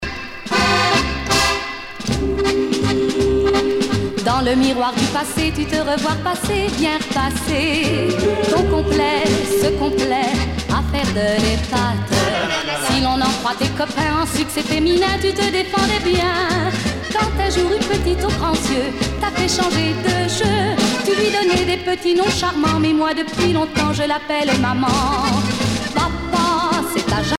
danse : java
Pièce musicale éditée